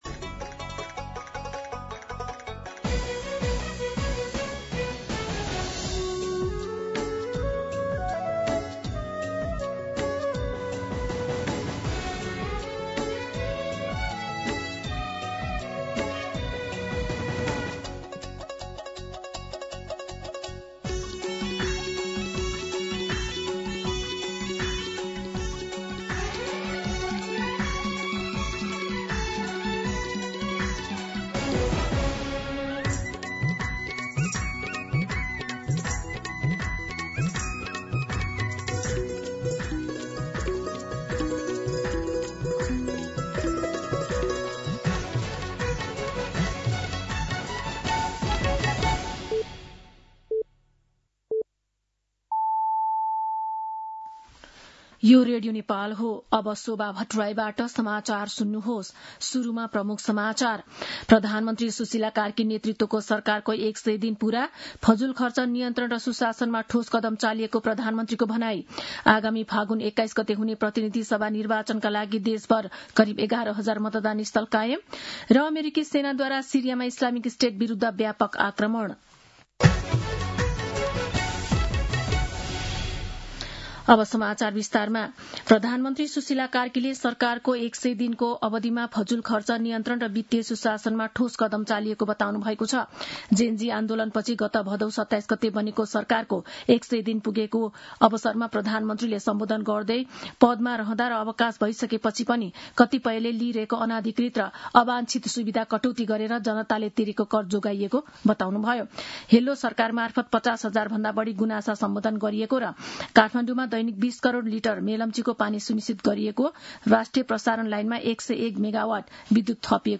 दिउँसो ३ बजेको नेपाली समाचार : ५ पुष , २०८२